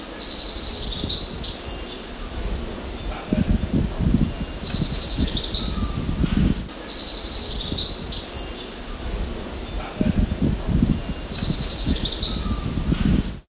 During the last few morning meditation sessions, birdsong has begun to accompany the demolition next door! When our neighbours are taking a rest, the birds are really apparent. It was so special to hear them this morning during our tea break that we thought of making a recording of them and sharing it with you all.